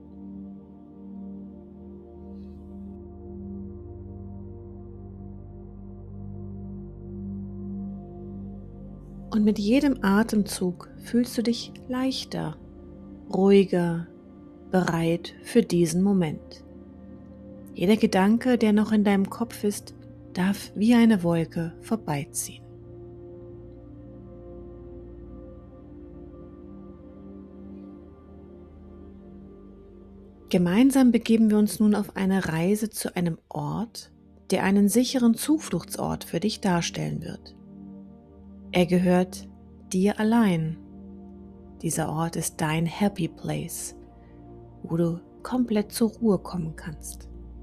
Fantasiereisen sind geführte Audioreisen, bei denen du mental an einen sicheren, naturnahen Ort geführt wirst. Du hörst sanfte Sprache, ruhige Musik und Naturgeräusche und stellst dir die Szenen vor – etwa einen Waldweg, eine Lichtung oder einen stillen See.
• Musik und Naturgeräusche unterstützen die Entspannung und das Eintauchen in die Bilder